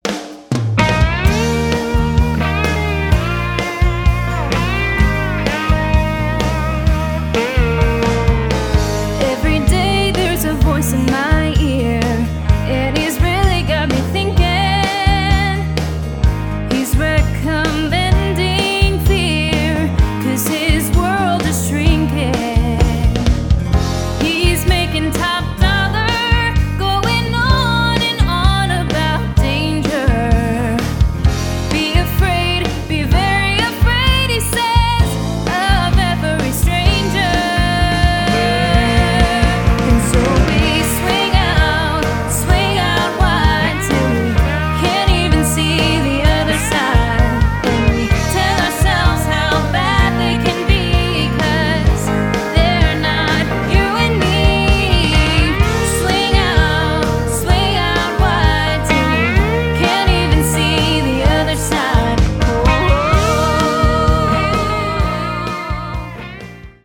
a wonderful young singer
pointed rockers